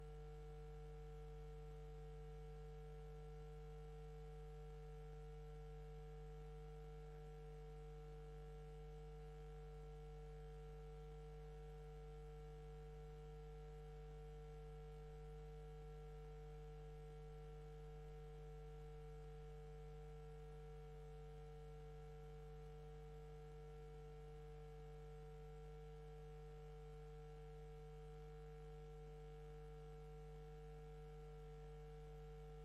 Informerende bijeenkomst 12 maart 2025 19:30:00, Gemeente Hof van Twente